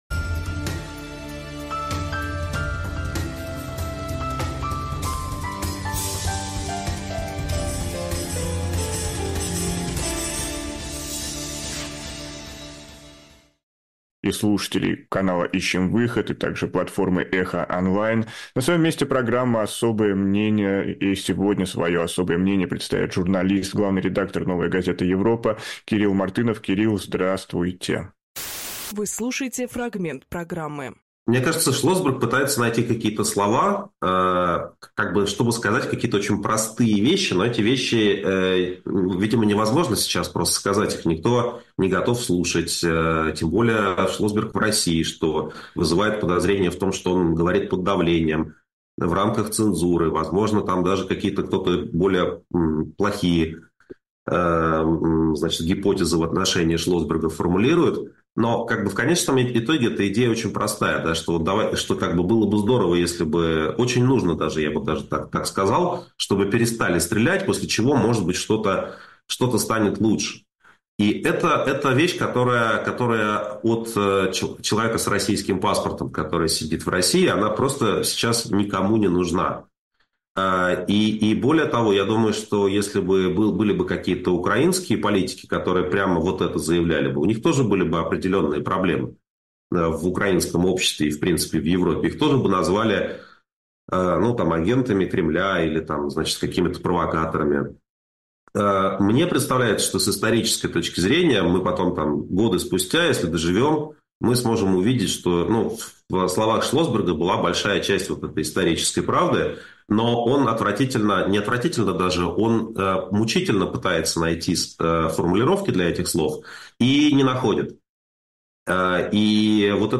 Фрагмент эфира от 14.11